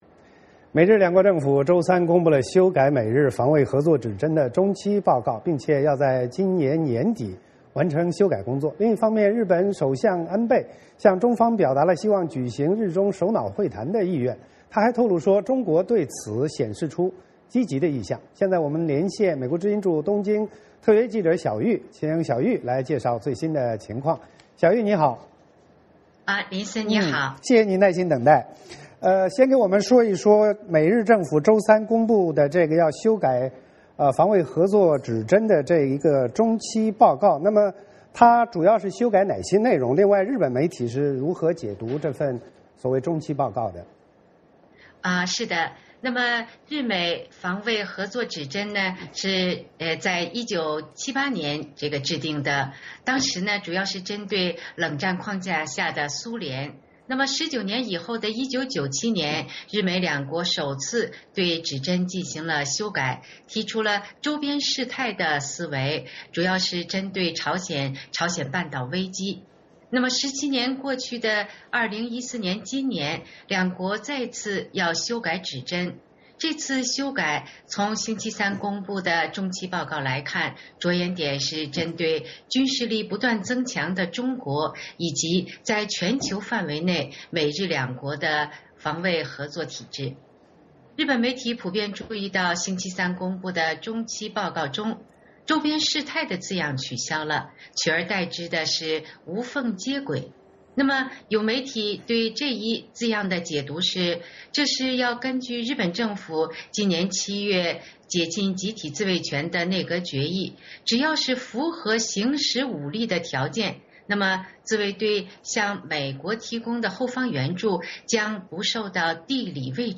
VOA连线：美日将修改防卫合作指针